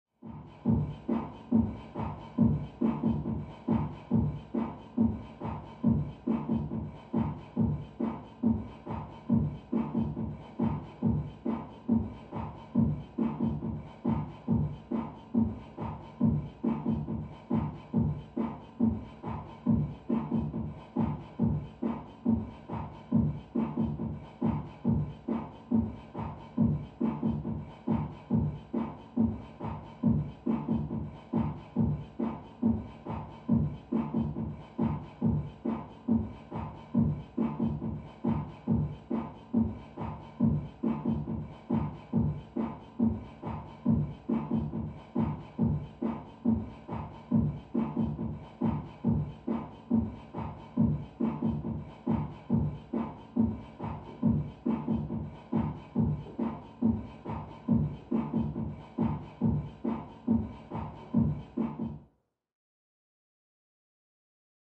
Music; Electronic Dance Beat, From Next Room.